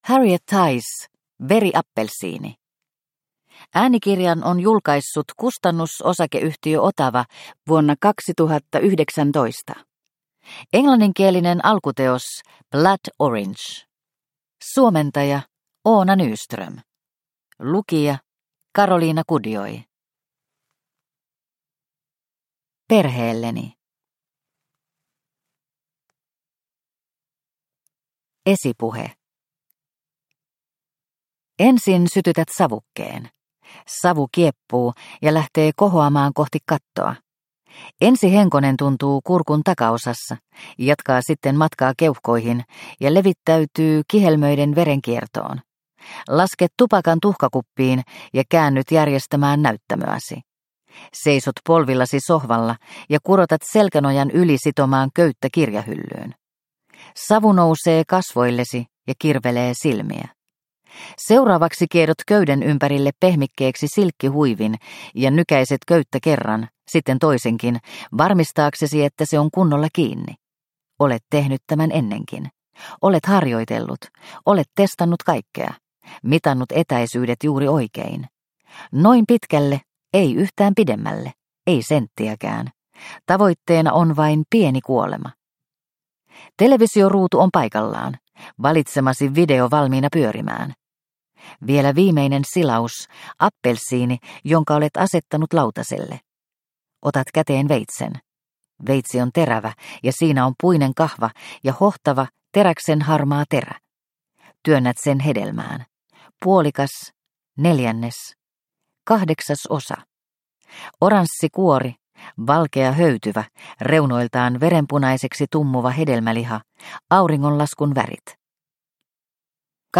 Veriappelsiini – Ljudbok – Laddas ner